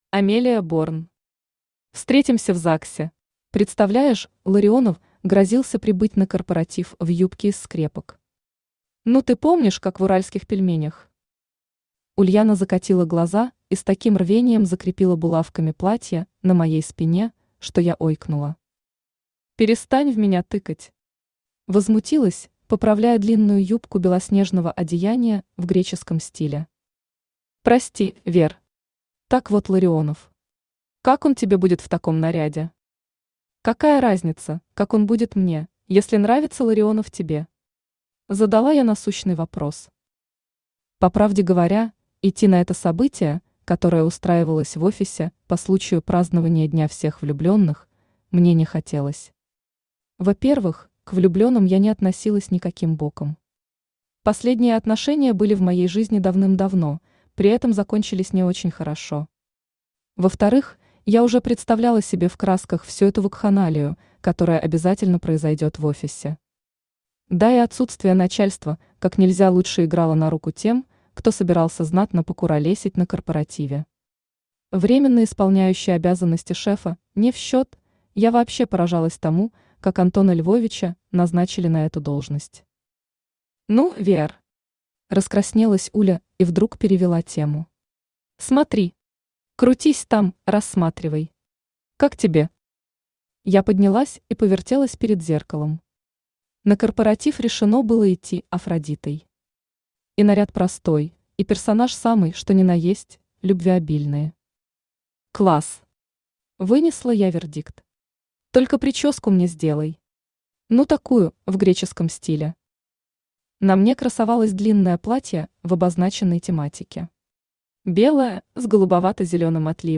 Автор Амелия Борн Читает аудиокнигу Авточтец ЛитРес.